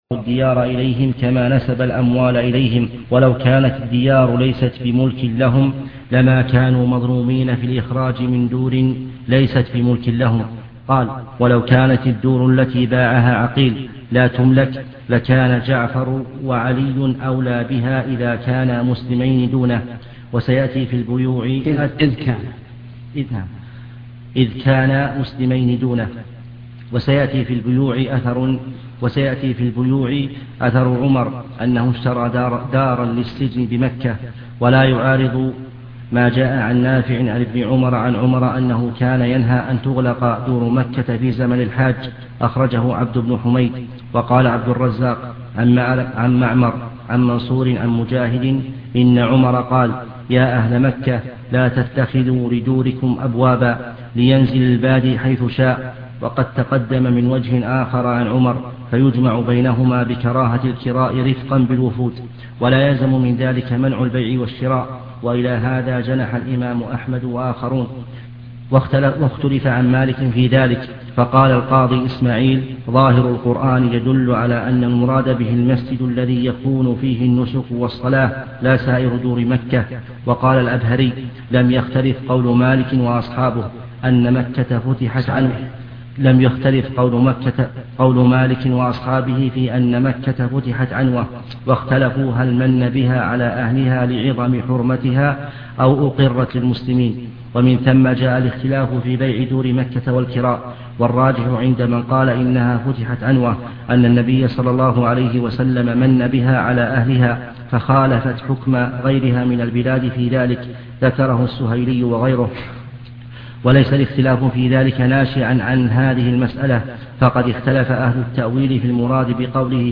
) وعن عروة أن عائشة رضي الله عنها أخبرته ( عن النبي صلى الله عليه وسلم في تمتعه بالعمرة إلى الحج فتمتع الناس معه بمثل الذي أخبرني سالم عن ابن عمر رضي الله عنهما عن رسول الله صلى الله عليه وسلم ) قراءة من الشرح مع تعليق الشيخ .